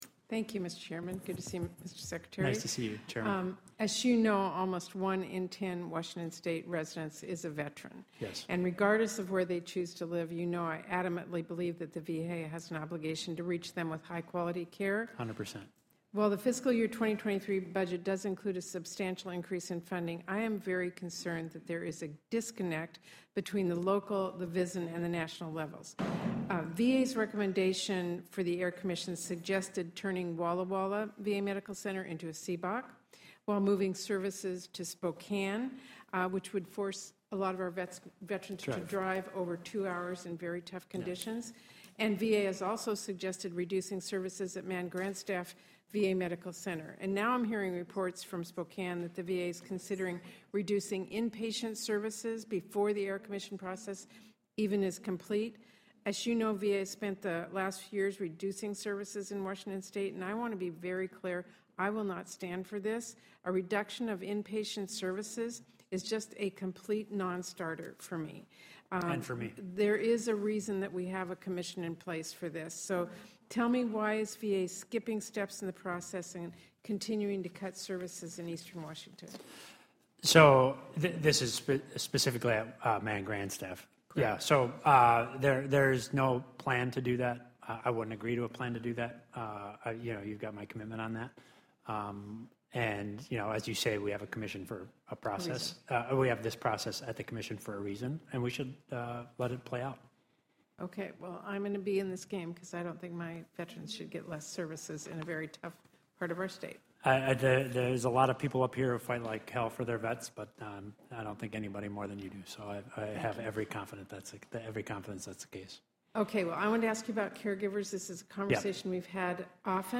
At Senate Hearing, Senator Murray Presses VA Secretary on Patient Care, Caregivers Program, and Reiterates Call to Stop EHR Rollout in Washington state
***LISTEN: SENATOR MURRAY’S FULL QUESTIONING
(Washington, D.C.) – Today, at a Senate Appropriations hearing on funding requests for the Department of Veterans Affairs (VA), Senator Patty Murray (D-WA), a senior member of the Senate Appropriations Committee, spoke with VA Secretary Denis McDonough on a number of her top priorities for Washington state veterans.